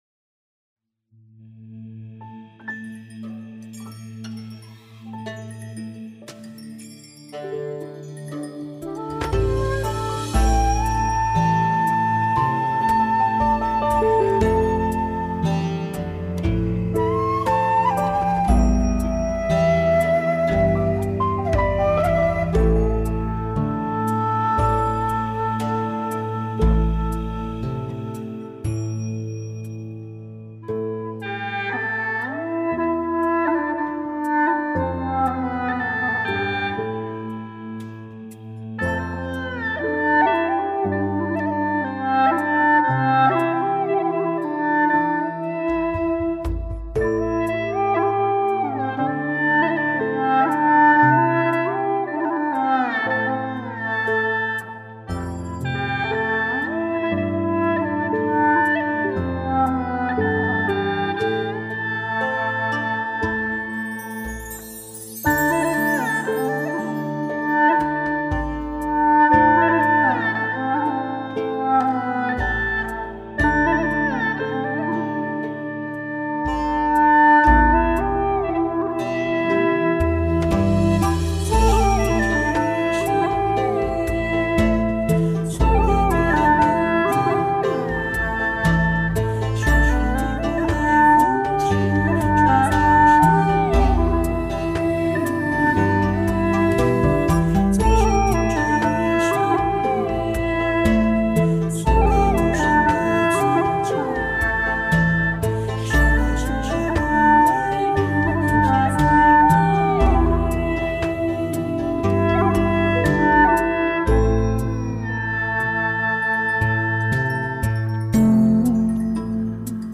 调式 : C 曲类 : 古风